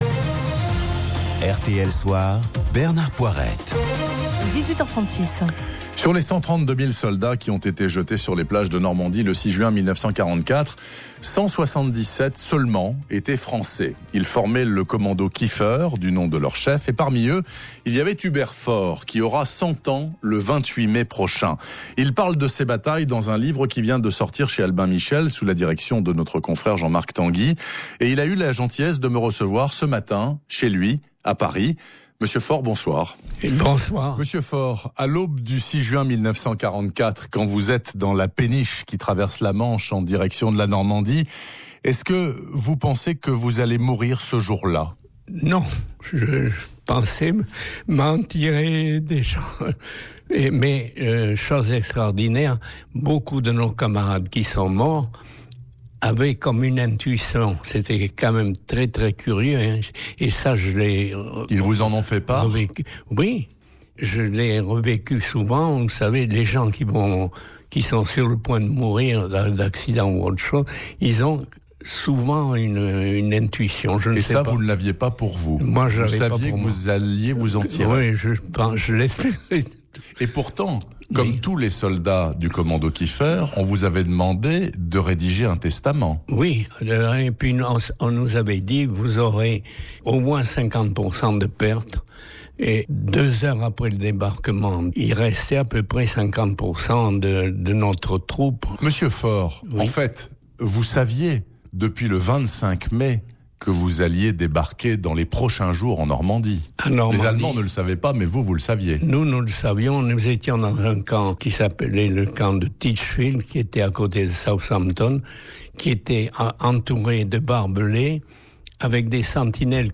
Interview RTL / Mai 2014 /